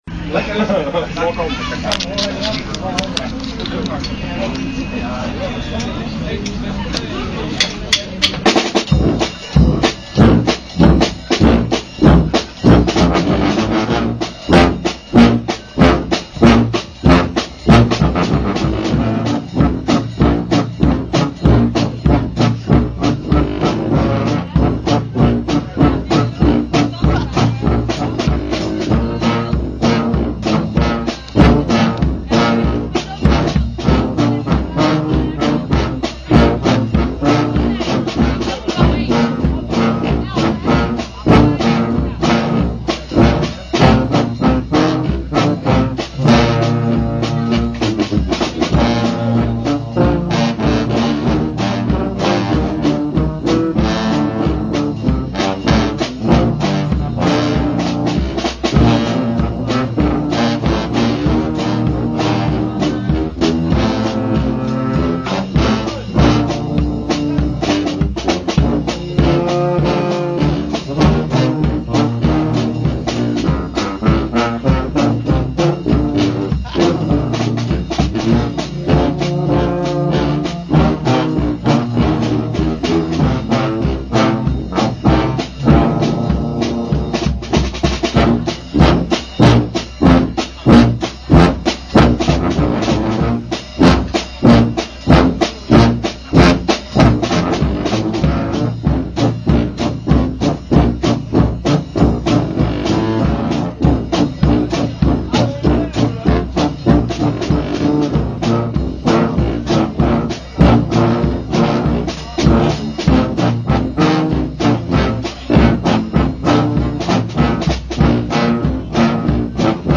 playing da TUBA